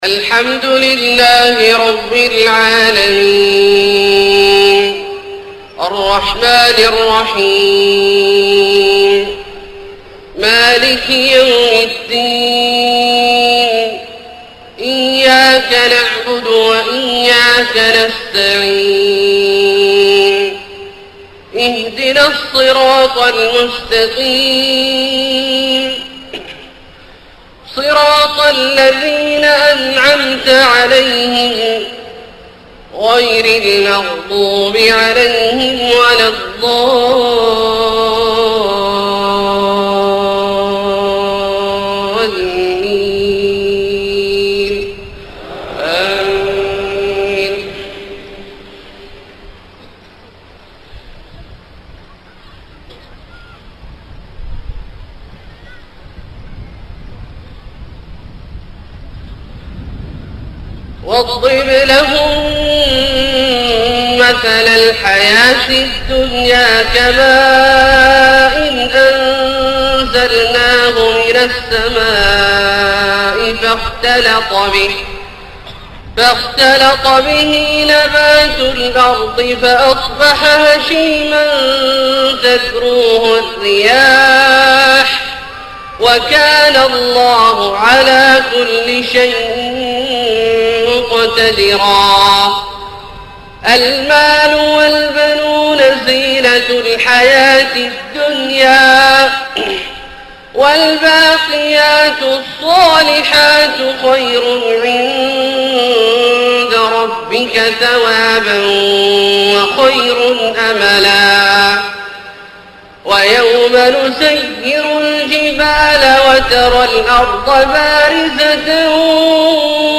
صلاة العشاء1-8-1431 من سورة الكهف {45-59} > ١٤٣١ هـ > الفروض - تلاوات عبدالله الجهني